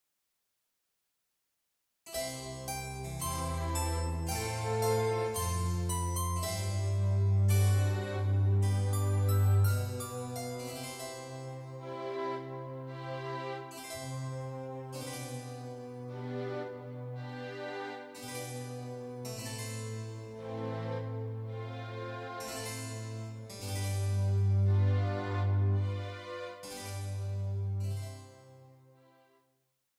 Répertoire pour Clarinette